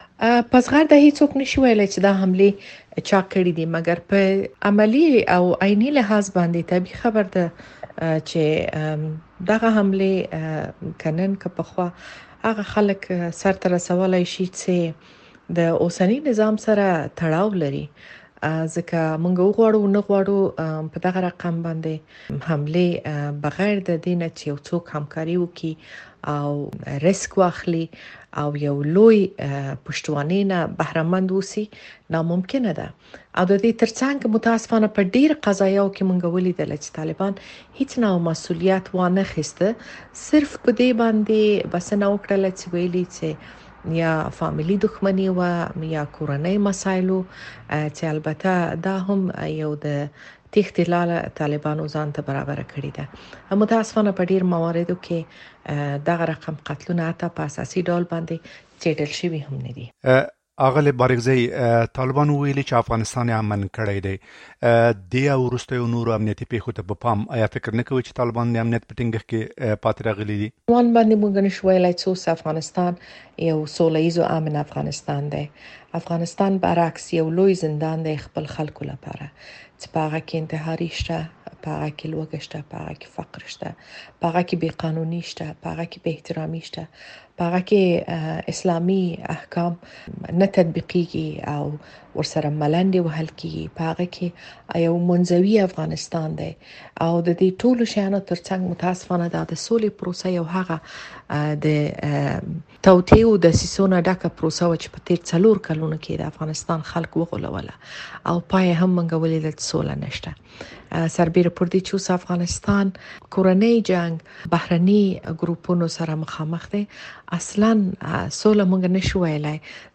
د شکريې بارکزۍ مرکه